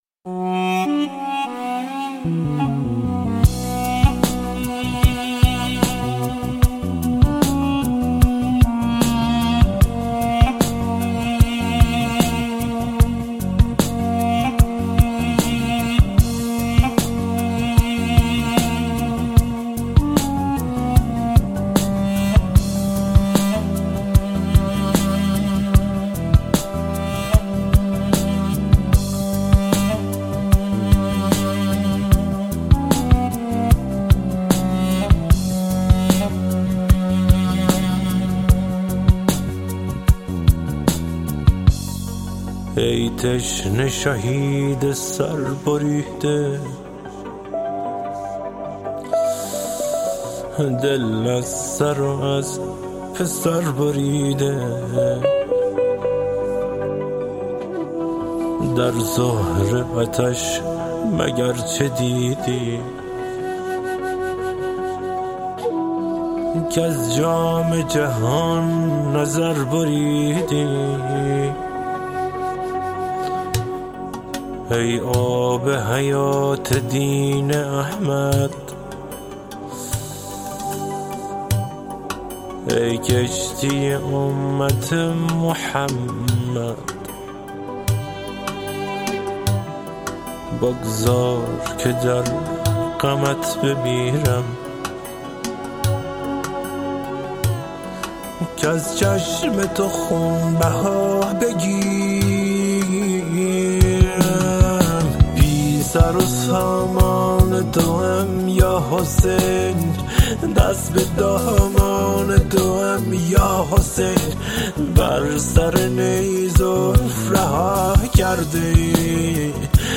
تک آهنگ‌های عاشورایی